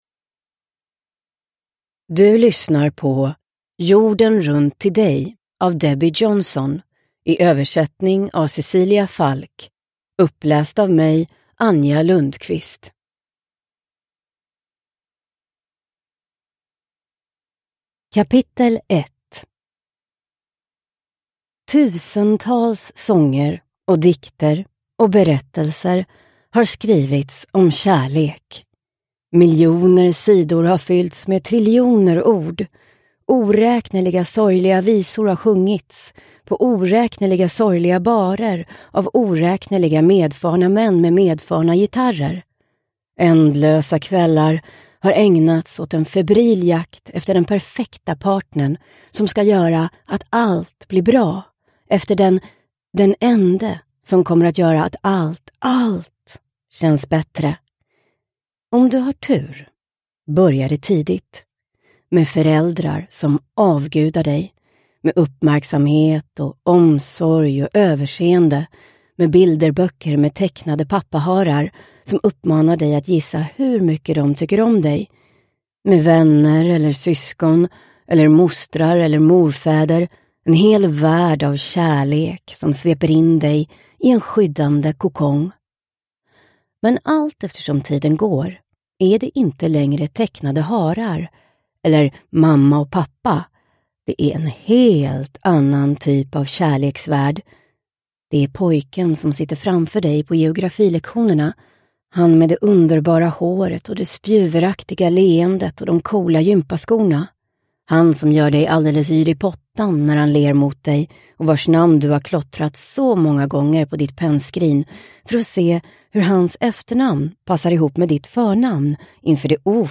Jorden runt till dig – Ljudbok – Laddas ner